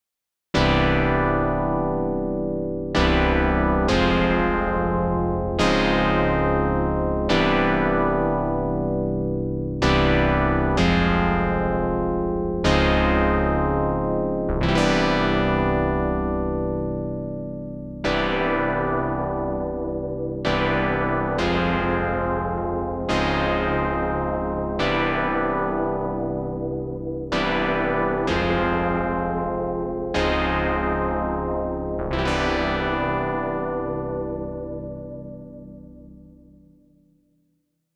Das roomMS ist quasi das schlechteste Reverb, das ich kenne.
Als Lofi Reverb macht es aber was her, eiert gut, sollte man vielleicht eher als Chorus sehen, einmal Dry, einmal Wet.